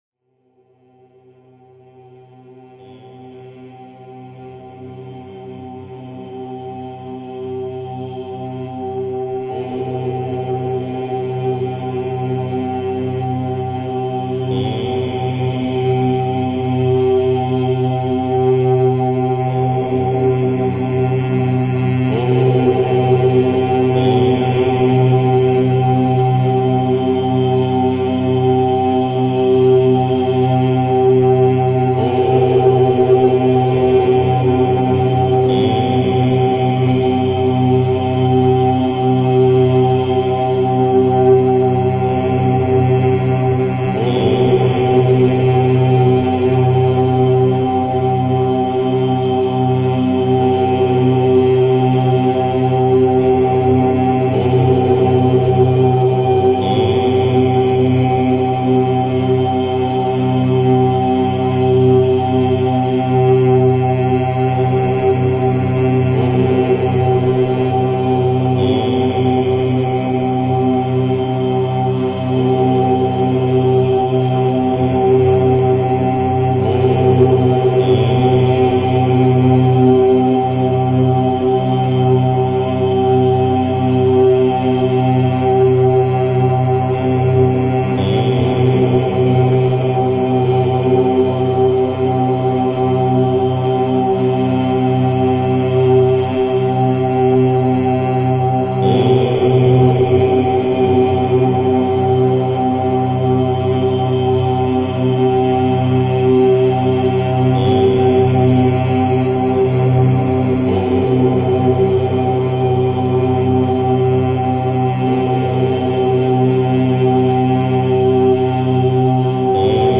..ОМ Мантра самая мощная и универсальная мантра Тибетских буддийских монахов..
мантра ॐ OM (AUM)